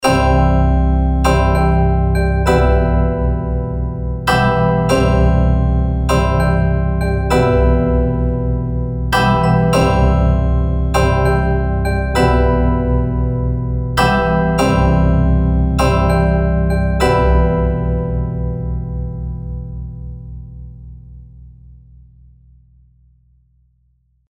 Beim DUO Celechimes liefert das Celeste das Volumen, das Toy Piano den glockigen Charakter und den Anschlag.
Eine einfache Begleitung aus Toontrack EZkeys Keys/Strings MIDI: